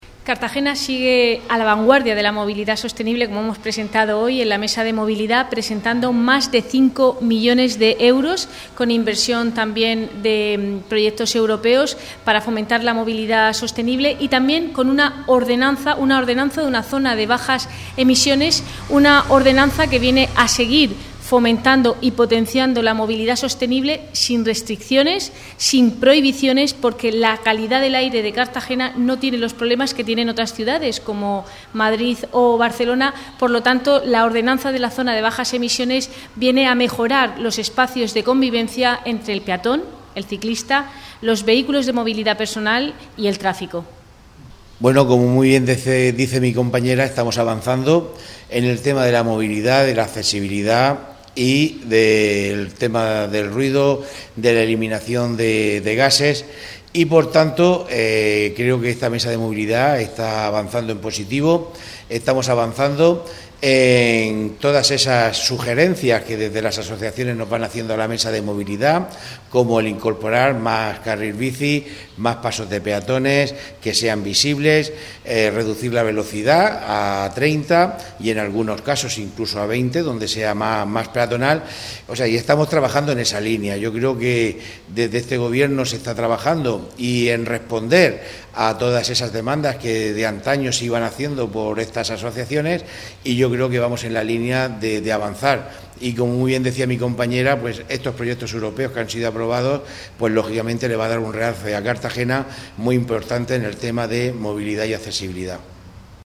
Declaraciones de Cristina Mora y Juan Pedro Torralba sobre la Mesa de la Movilidad (MP3 - 1,02 MB) La Mesa de la Movilidad expone a los colectivos las l�neas maestras de la ordenanza de Zona de Bajas Emisiones de Cartagena